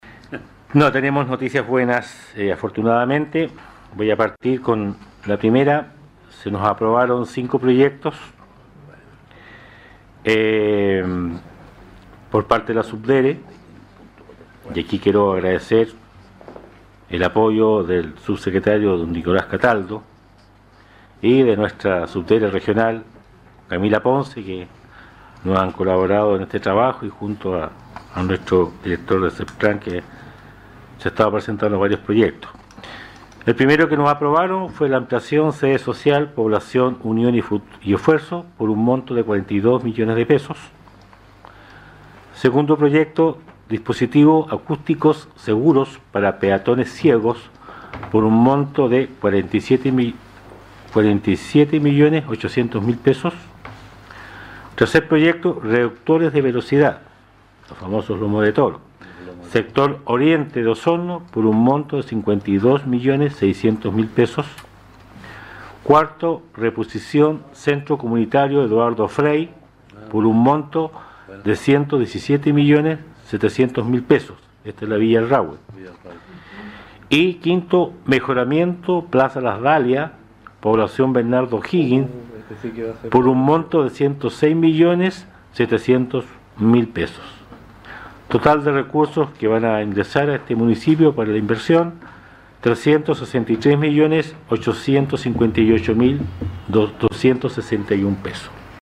El alcalde de Osorno, Emeterio Carrillo, informó ayer en la sesión ordinaria del Concejo Municipal que se recibió la aprobación por parte de la SUBDERE de recursos para ejecutar una serie de proyectos, que fueron presentados con anterioridad.